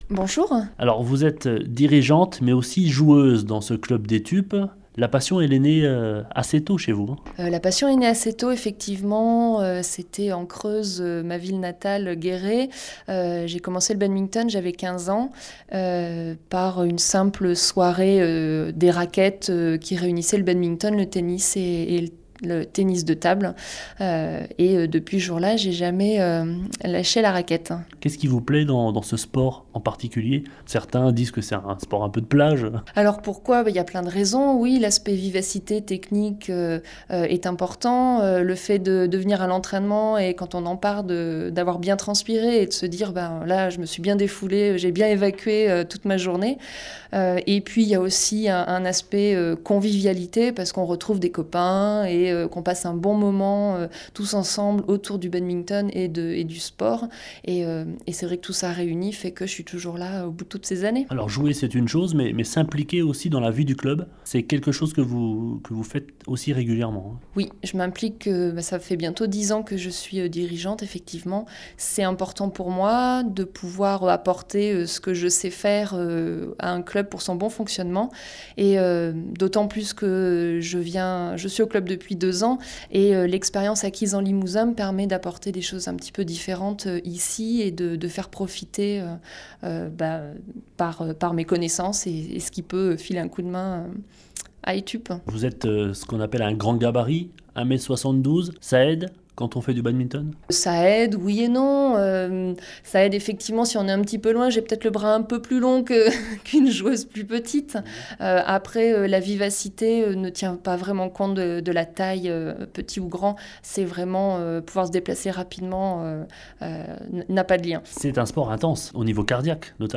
Le Badminton Club Etupes sera à l’antenne de France Bleu Belfort Montbéliard dans la chronique passion sport.